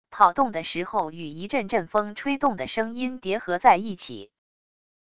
跑动的时候与一阵阵风吹动的声音叠合在一起wav音频